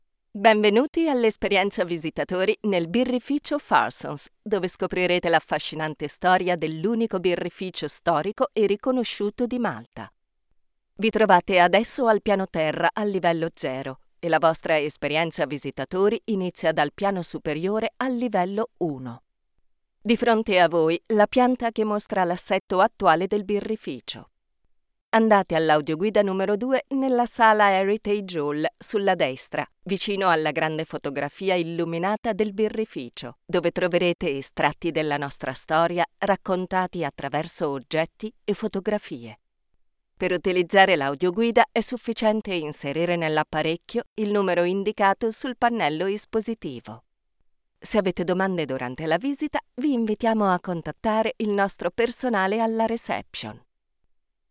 articulate, authentic, Deep, elegant, empathic, Formal, friendly
I record every day in my Home Studio: commercials, e-learning courses, tutorials, documentaries, audio guides, messages for telephone exchanges, audio for promo videos, narrations for radio broadcasts, audiobooks, audio for videogames, and much more.